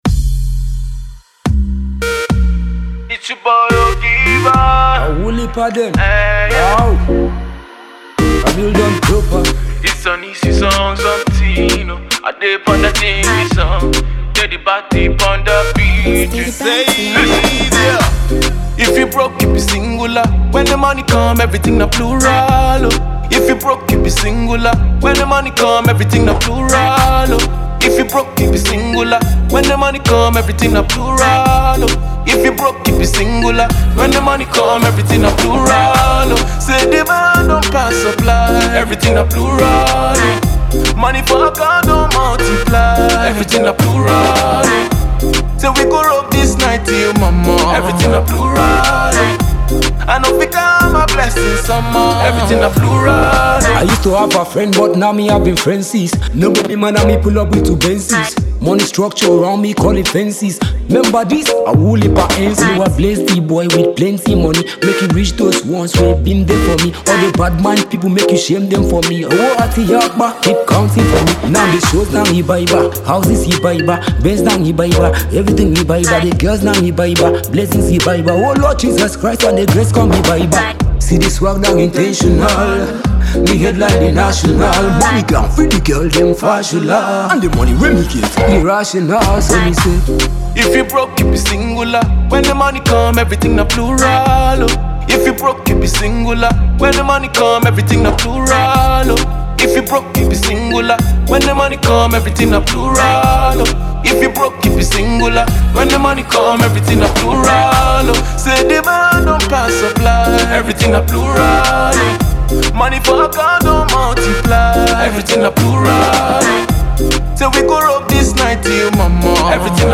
Reggae Dance Hall